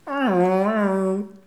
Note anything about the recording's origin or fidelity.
Les sons ont été découpés en morceaux exploitables. 2017-04-10 17:58:57 +02:00 256 KiB Raw History